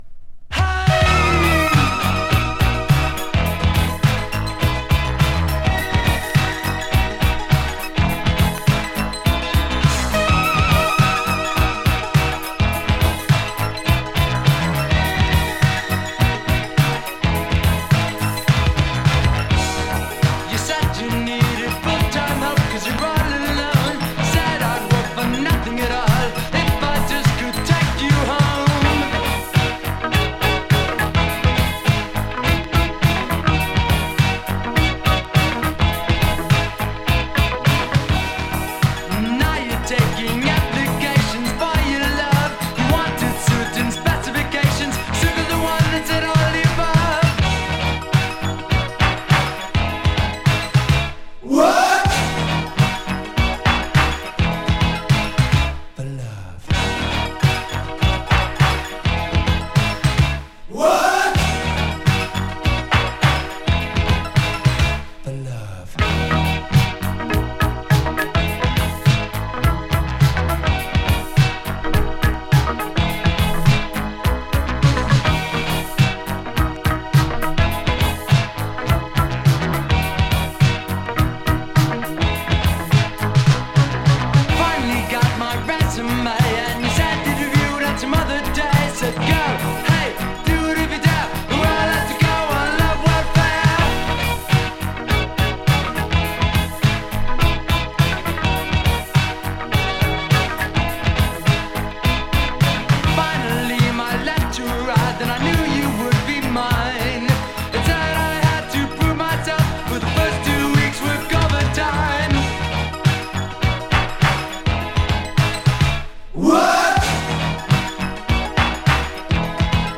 【NEW WAVE】 【DISCO】
GARAGE CLASSIC!